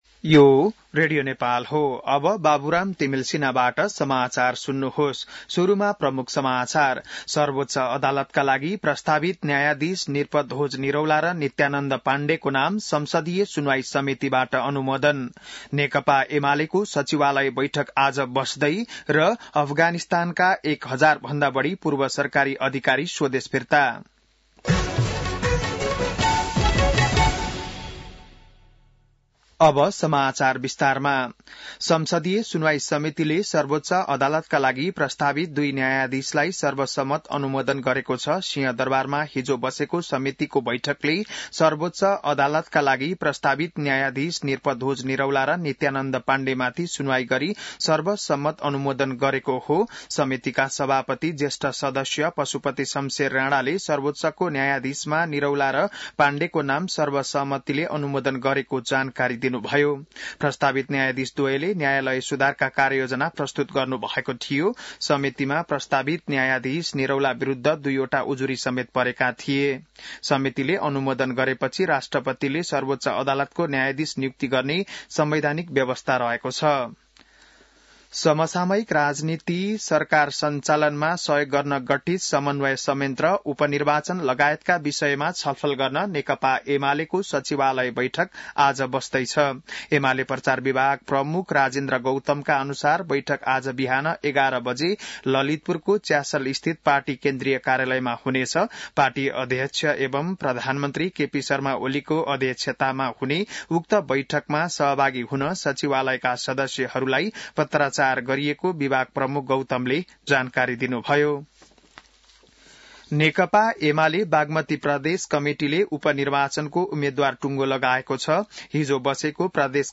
An online outlet of Nepal's national radio broadcaster
बिहान ९ बजेको नेपाली समाचार : २५ कार्तिक , २०८१